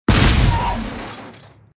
debri explo 3.wav